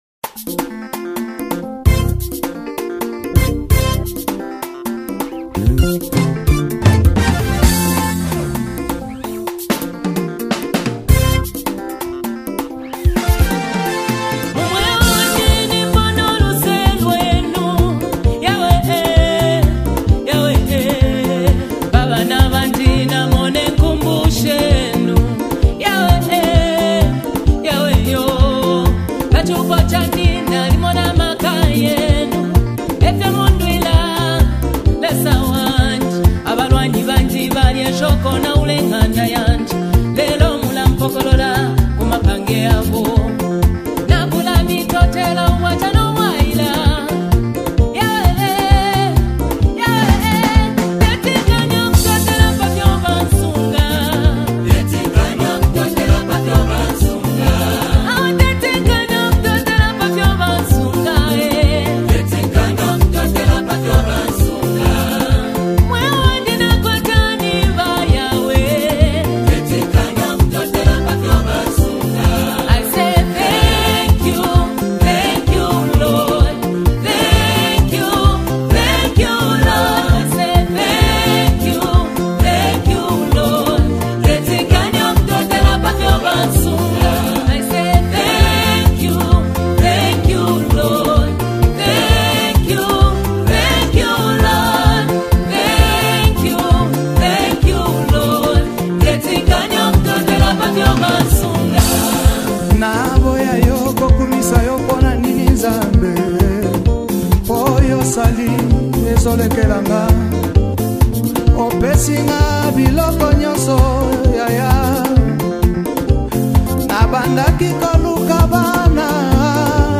worship anthem